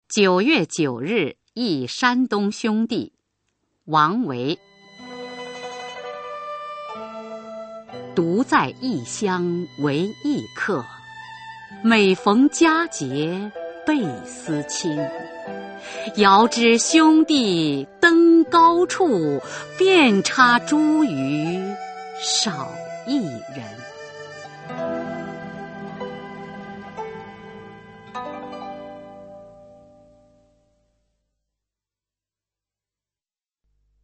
[隋唐诗词诵读]王维-九月九日忆山东兄弟（女） 配乐诗朗诵